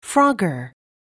듣기반복듣기 미국 [frɔ́ːgər, frάg-]